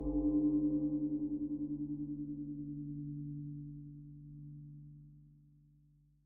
carmen distortion bell Meme Sound Effect
carmen distortion bell.mp3